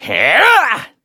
Kibera-Vox_Attack6_kr.wav